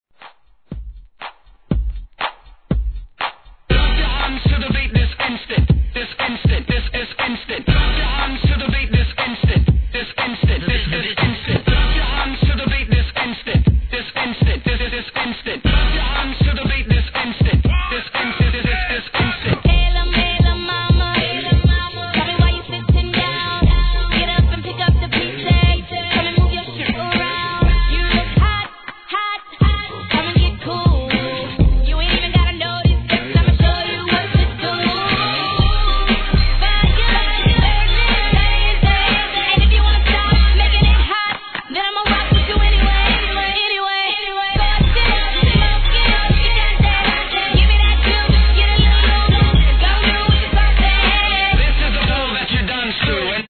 HIP HOP/R&B
BPM120